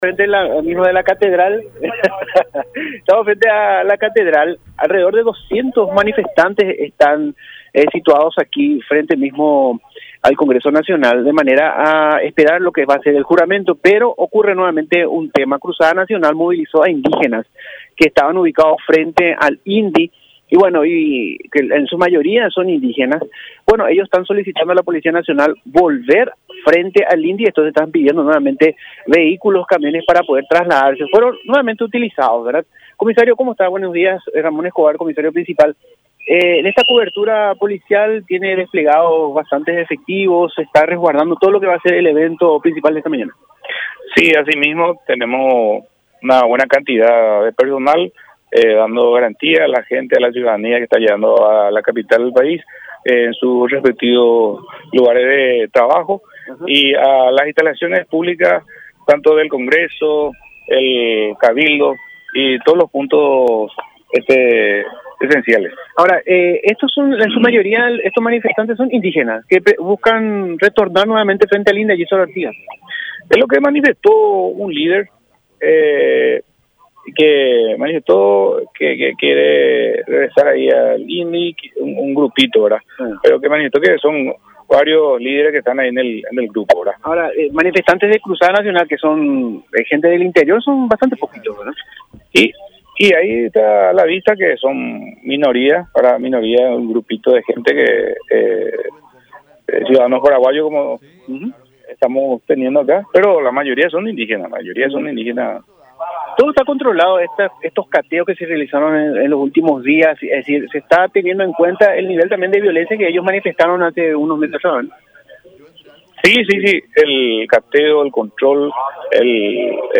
comentó el agente policial en charla con